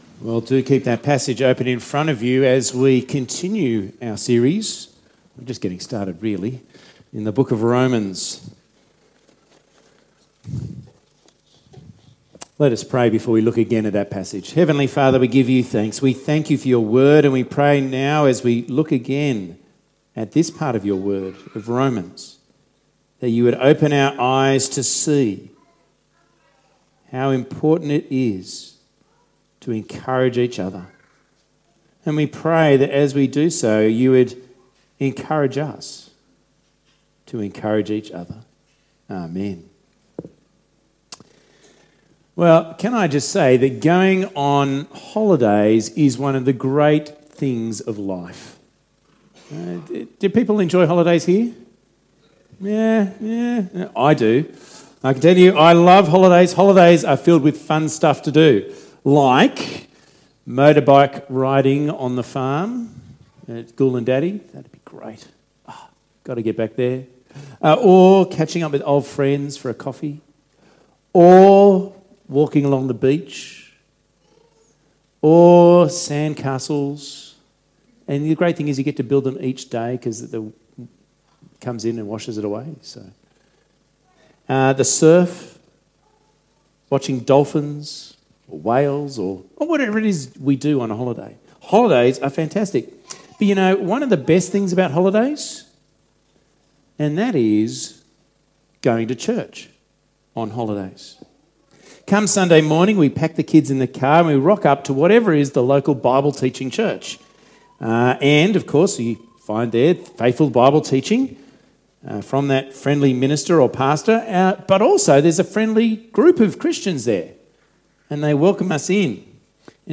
A new sermon is available: